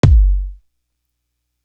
D Elite Kick.wav